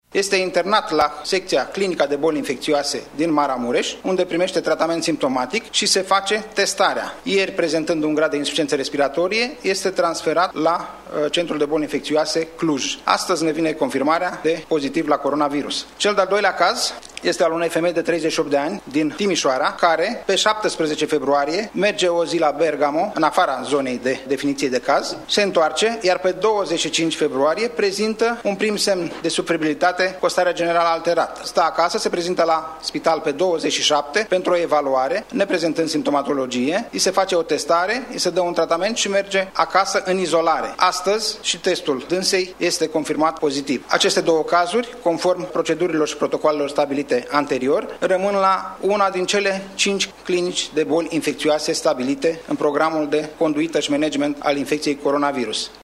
Acestea au apărut la o femeie din  Timișoara, de 38 de ani, și la un bărbat de 45 de ani, din Maramureș, a anunțat, într-o conferinţă de presă care a avut loc la sediul MAI,  secretarul de stat în Ministerul Sănătății, Nelu Tătar: